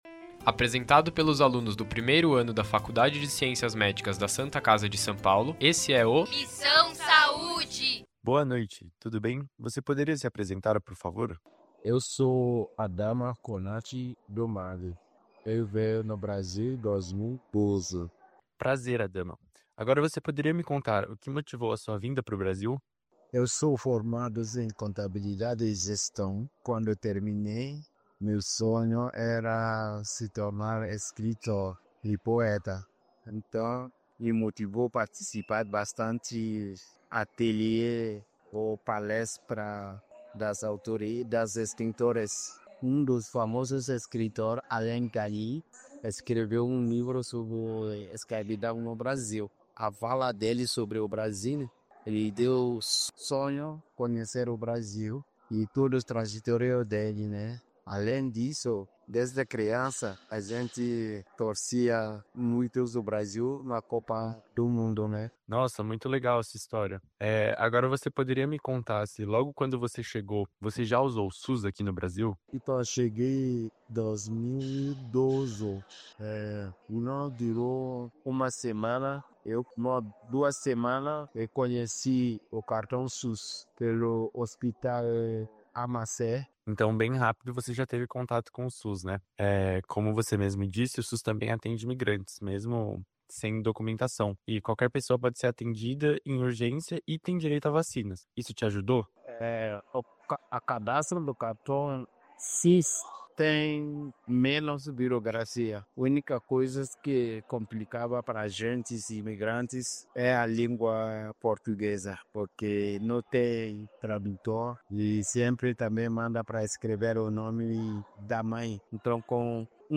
Podcasts : Entrevista a migrante do Mali sobre atendimento no SUS
Gravado e editado nos Estudios da Web Radio Migrantes